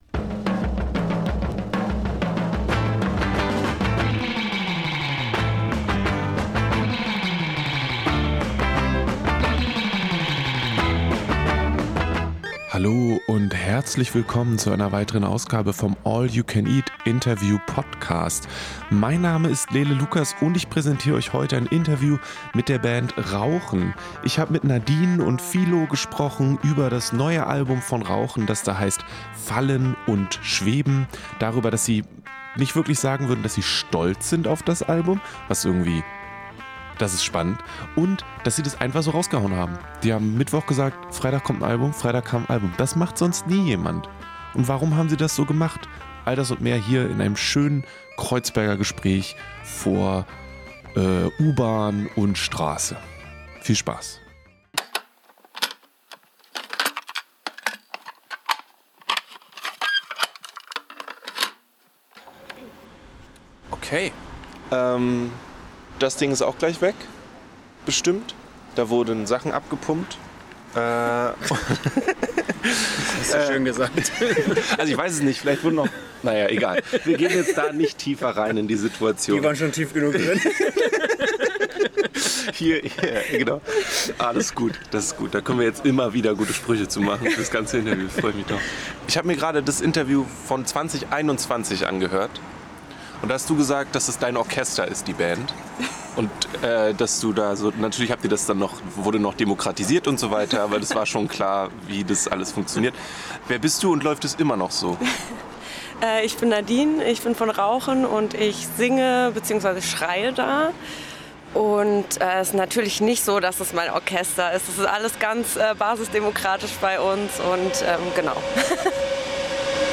Fallen und Schweben – Ein Interview mit RAUCHEN (2025)